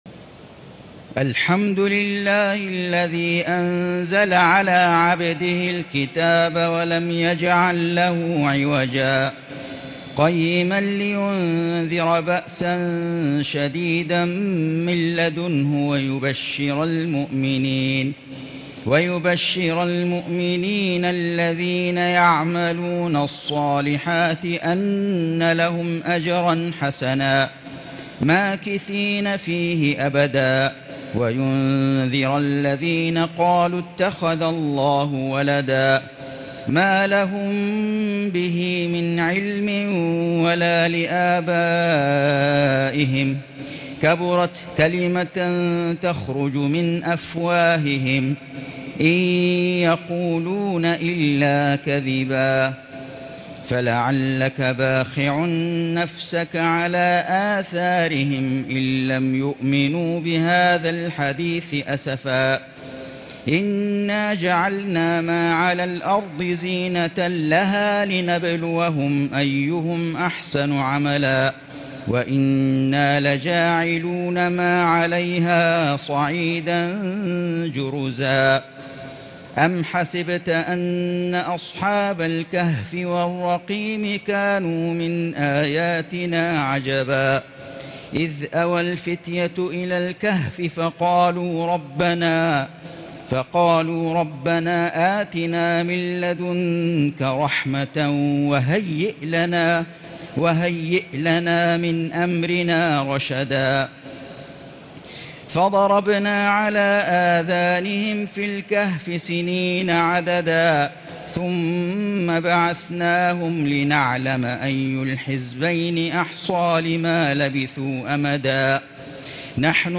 سورة الكهف > السور المكتملة للشيخ فيصل غزاوي من الحرم المكي 🕋 > السور المكتملة 🕋 > المزيد - تلاوات الحرمين